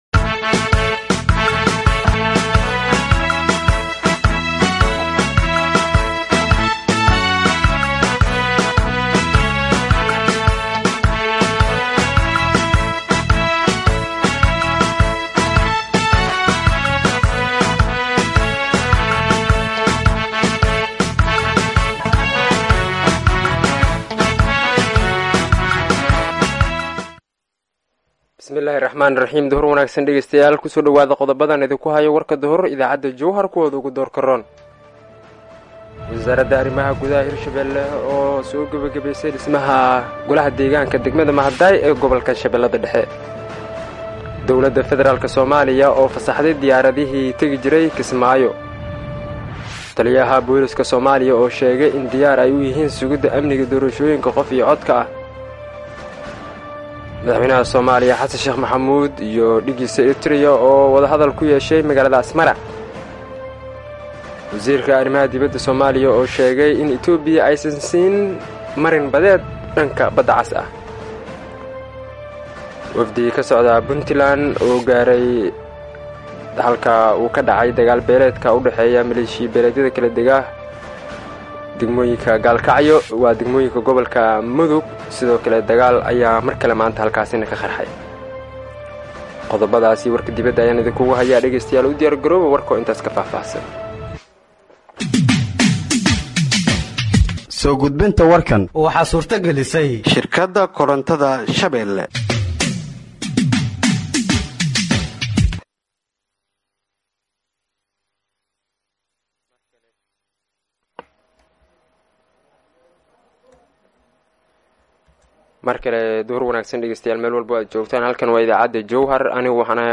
Halkaan Hoose ka Dhageeyso Warka Duhurnimo ee Radiojowhar